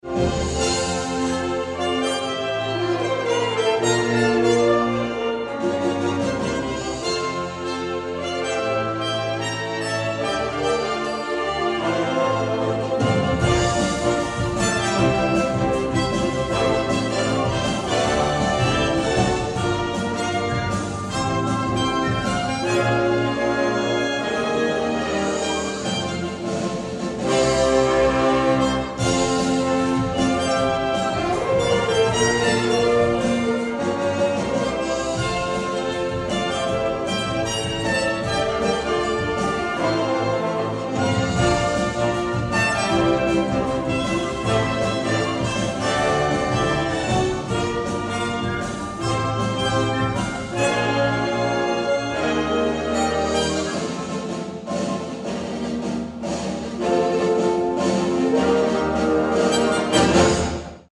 w wykonaniu naszej orkiestry